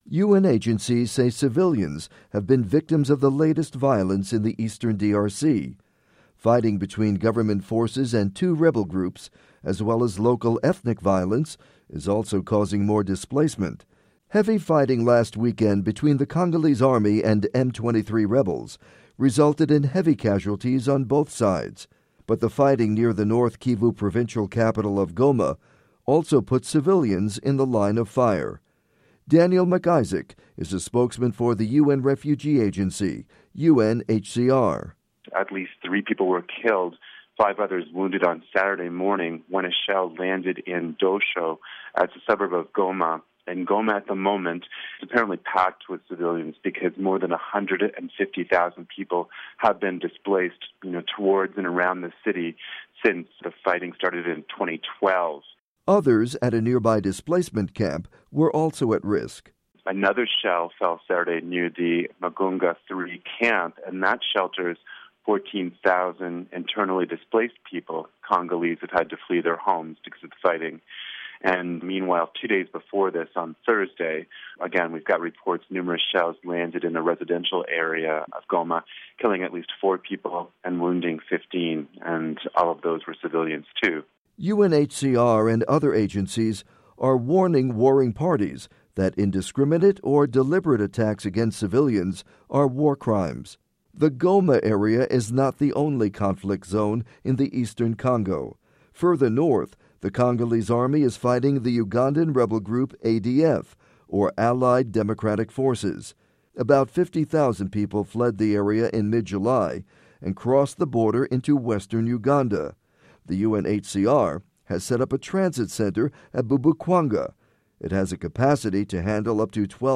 by Voice of America (VOA News)